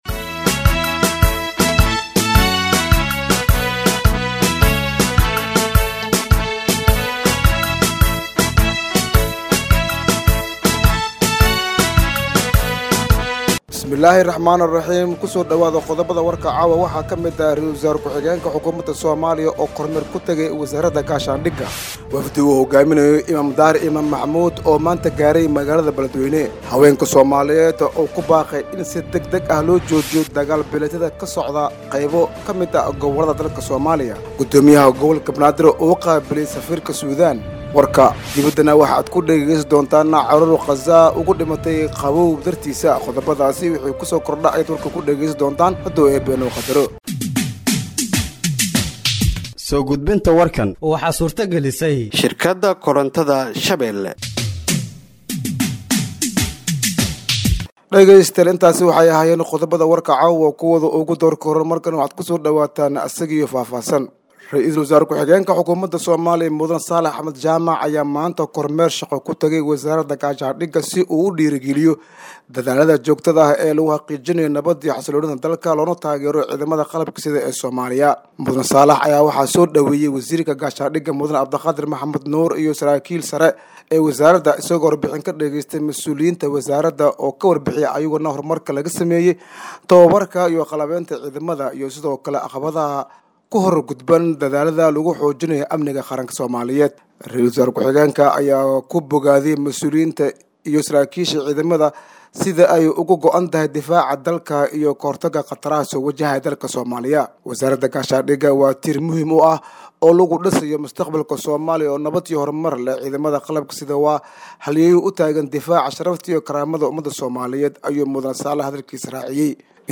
Dhageeyso Warka Habeenimo ee Radiojowhar 31/12/2024